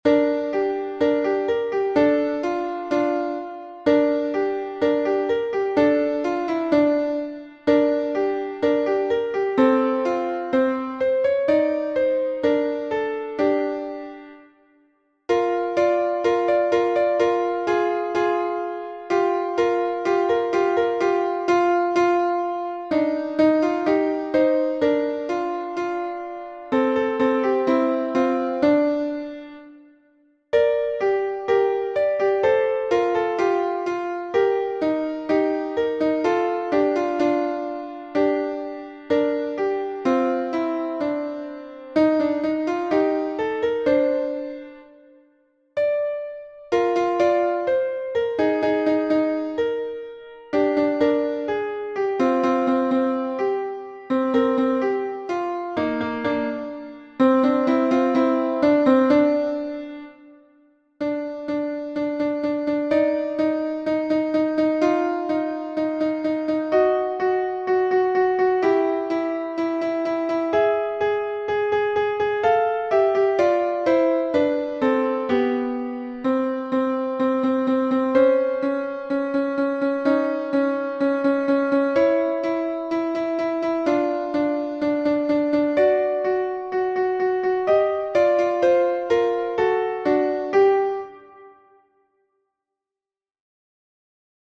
LA_SOUPE_A_LA_SORCIERE_tutti